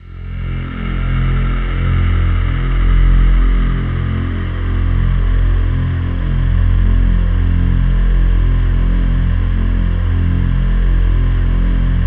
DM PAD1-30.wav